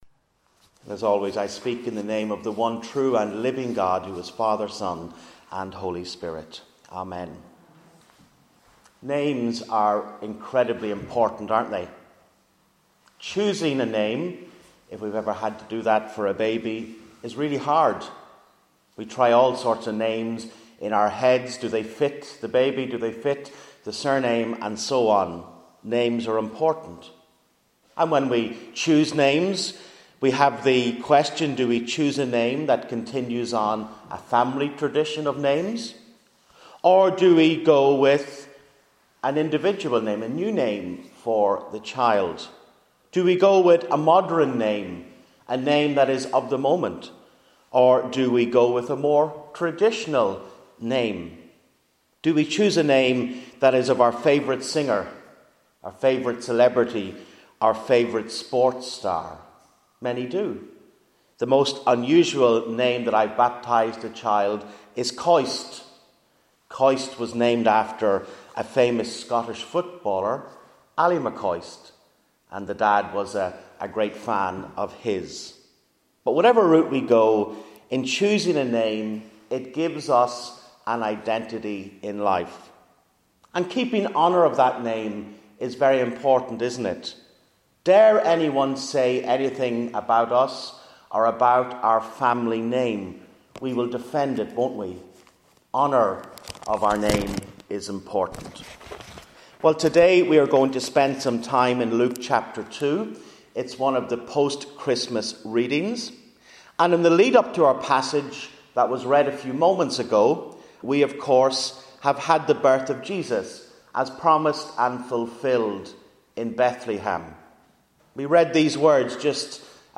General Sermons 2017 - Virginia Group Church Of Ireland